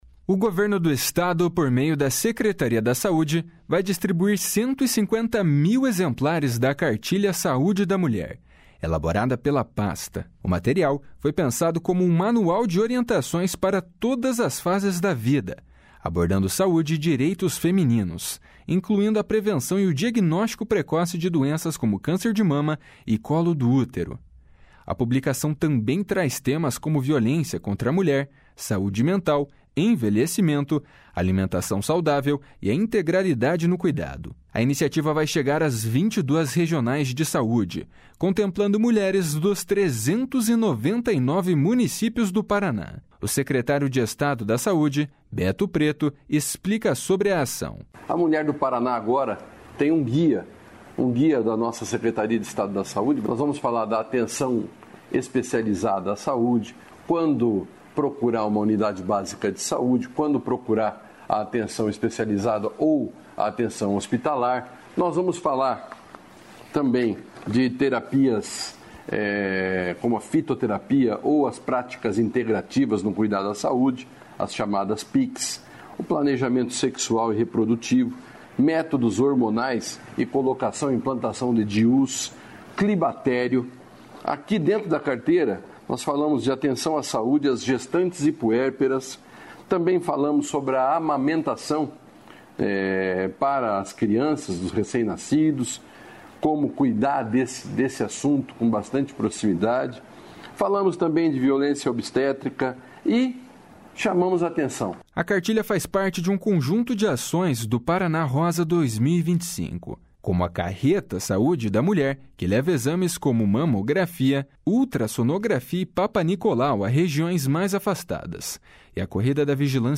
O secretário de Estado da Saúde, Beto Preto, explica sobre a ação. // SONORA BETO PRETO //